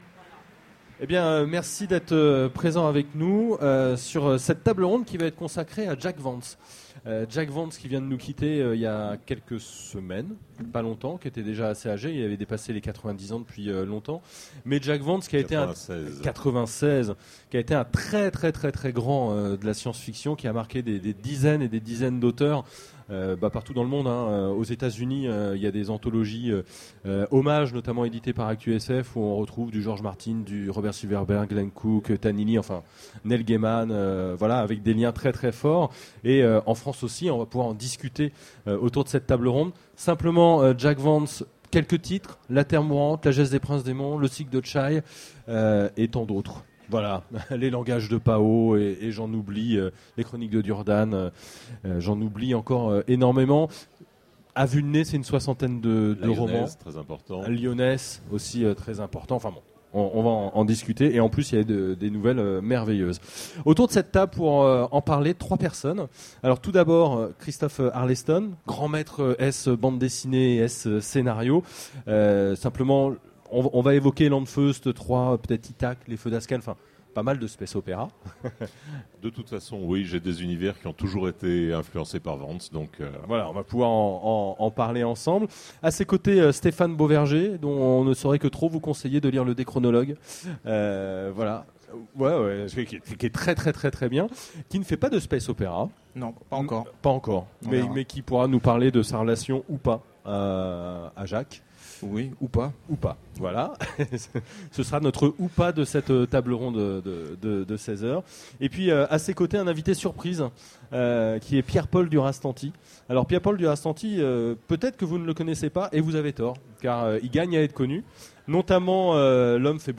Utopiales 13 : Conférence Hommage : Les mondes d'azur de Jack Vance